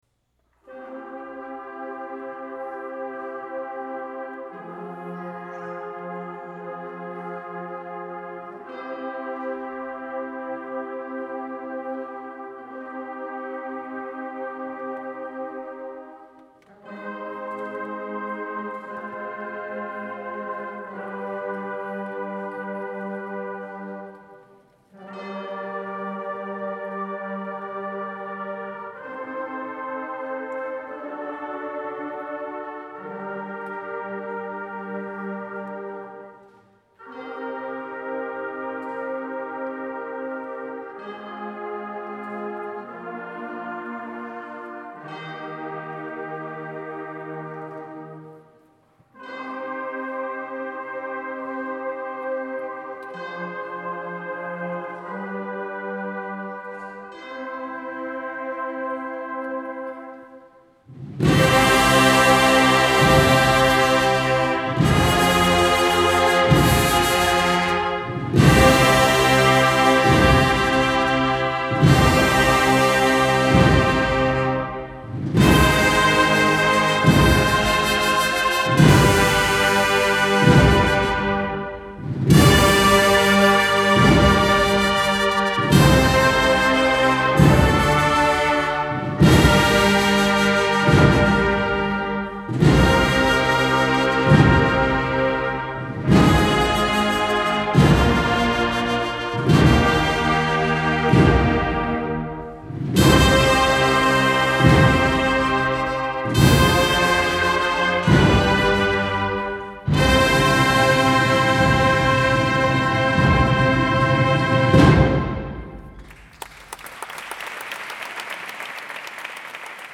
Hasta cinco adaptaciones sonaron durante el concierto.